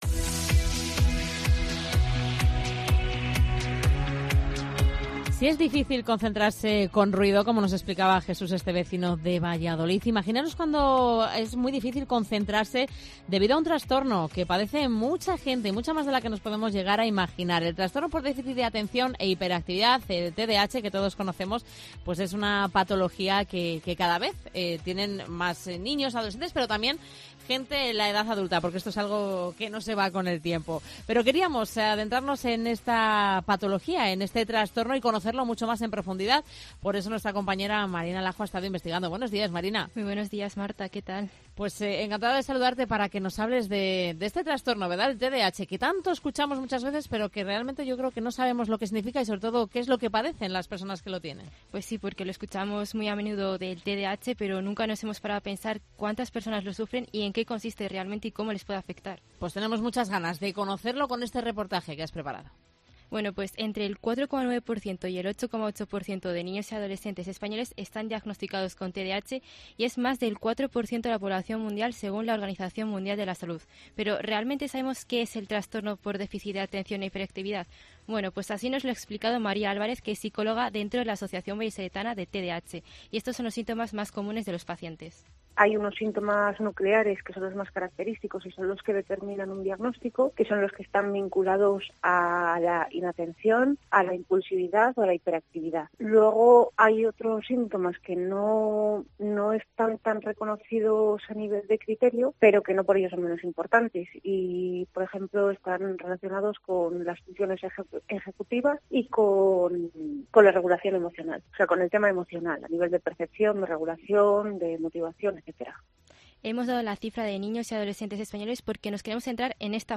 Reportaje TDAH